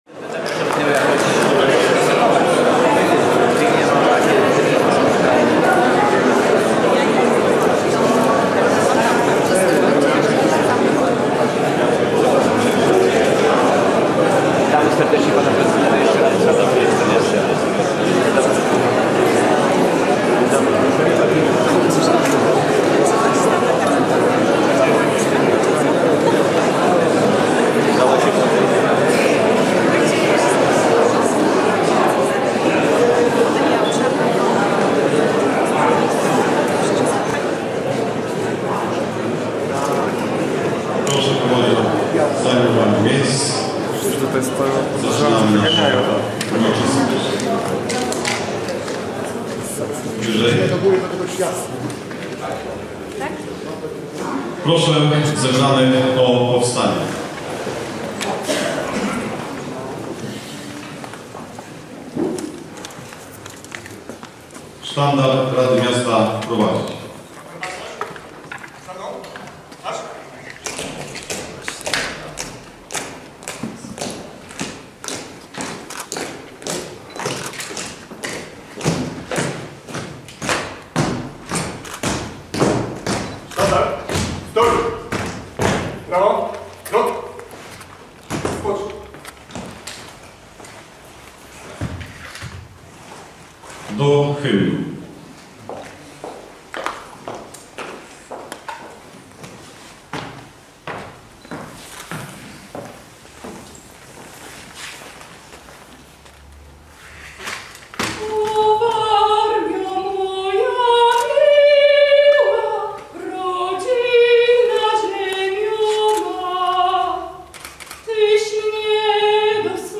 II część – na dzień 23 listopada br., na godz. 9:00, w Urzędzie Miasta Olsztyna przy pl. Jana Pawła II 1 w sali 219. 1.
Zaprzysiężenie-Piotra-Grzymowicza-na-Prezydenta-Olsztyna.mp3